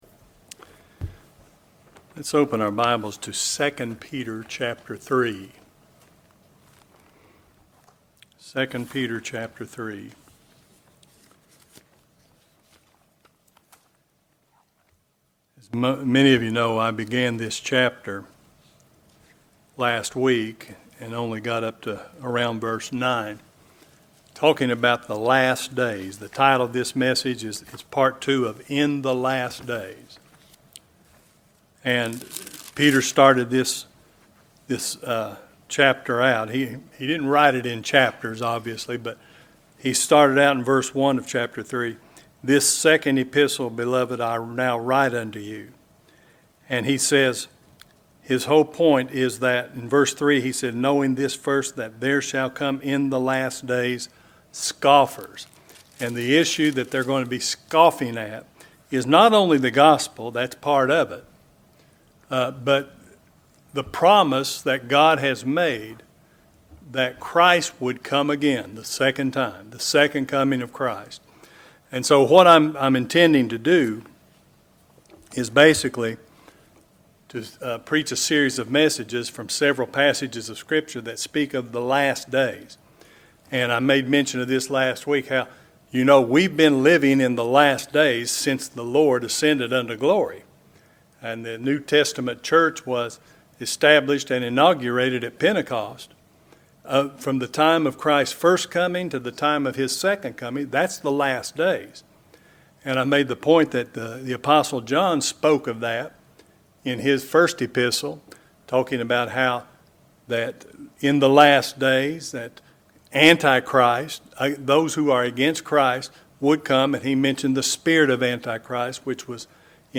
In the Last Days (2) | SermonAudio Broadcaster is Live View the Live Stream Share this sermon Disabled by adblocker Copy URL Copied!